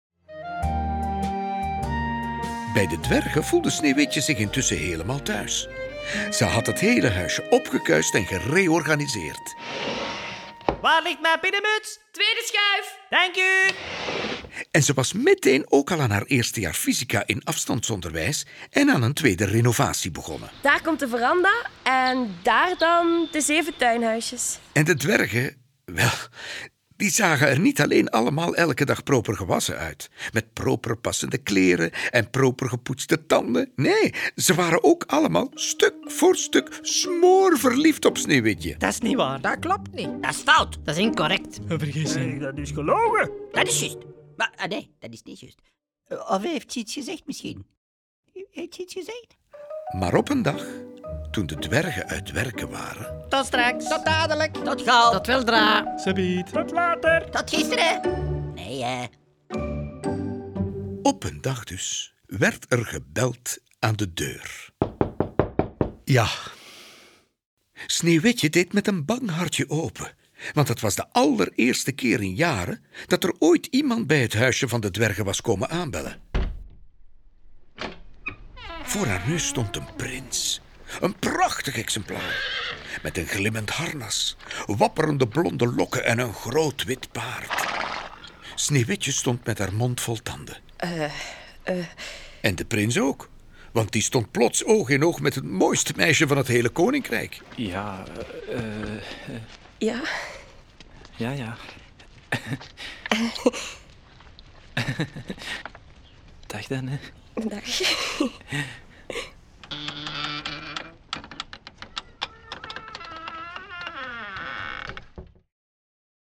Klarinet en Basklarinet
Viool